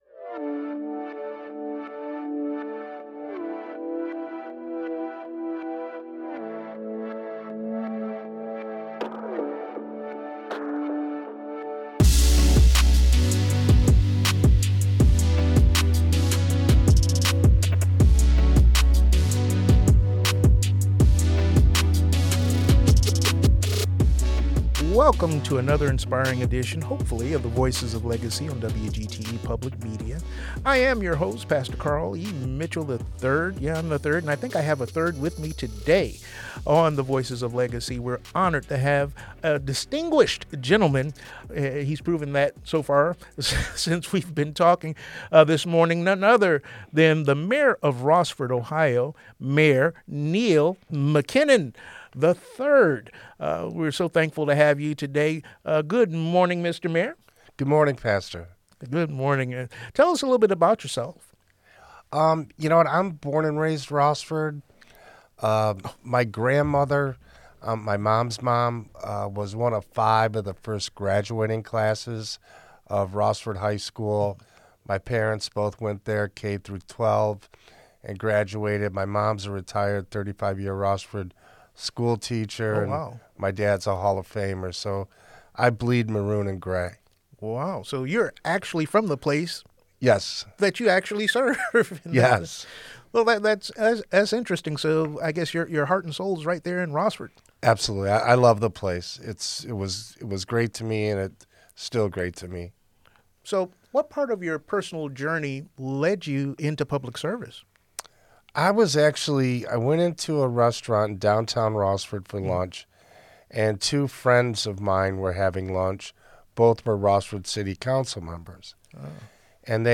Rossford’s Vision for the Future: A Conversation with Mayor Neil MacKinnon - WGTE Public Media